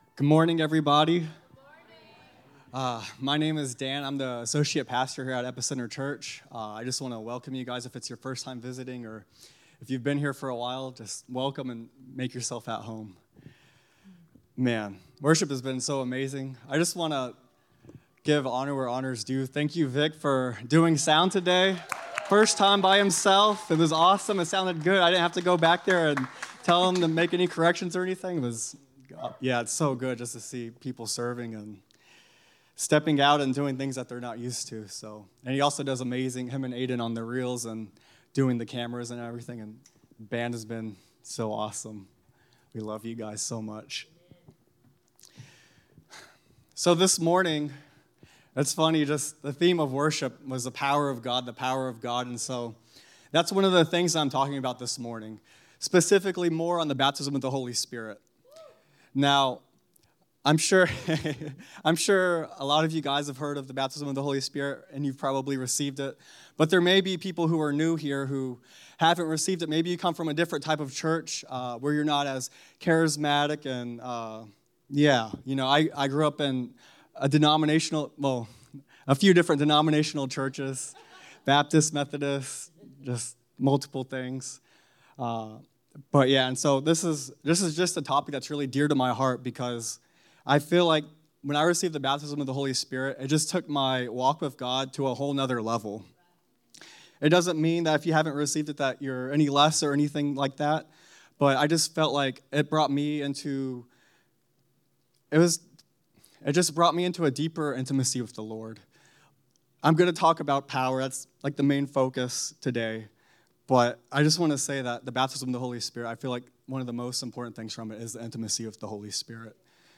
Empowered to Witness – SERMONS